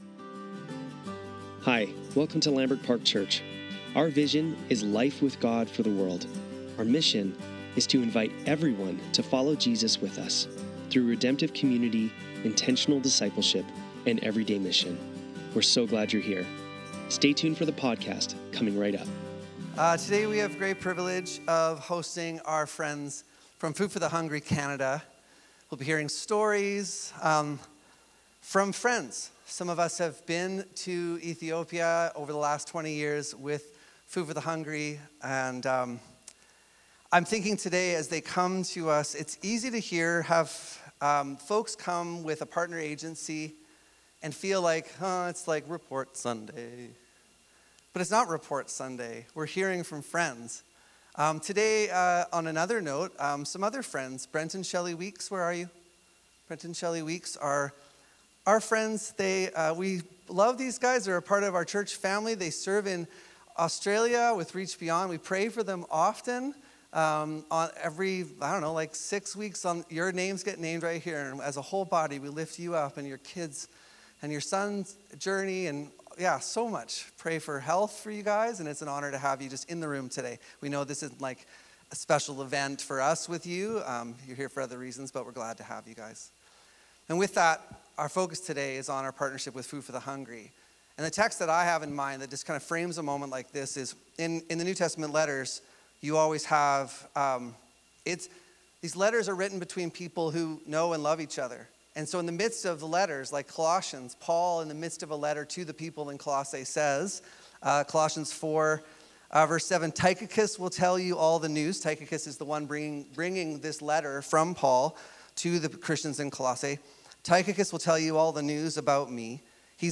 Sunday Service - March 1, 2026
Current Sermon